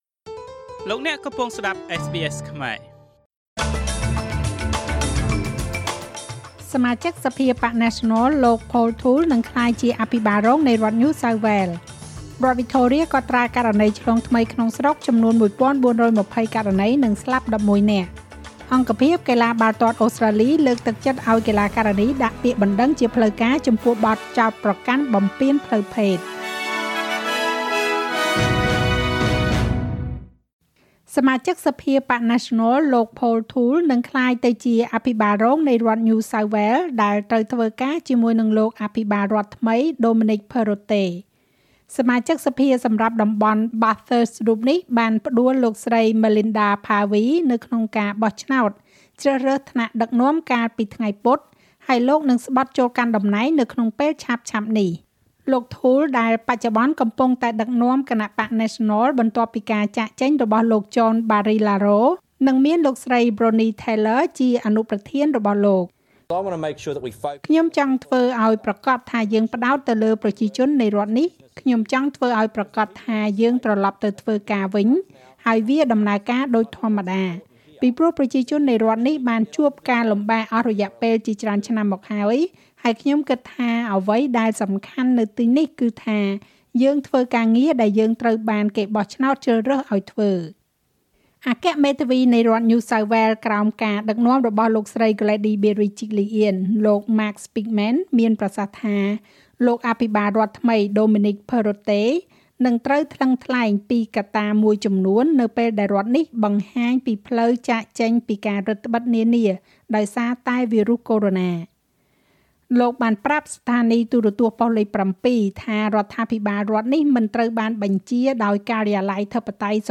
នាទីព័ត៌មានរបស់SBSខ្មែរ សម្រាប់ថ្ងៃពុធ ទី៦ ខែតុលា ឆ្នាំ២០២១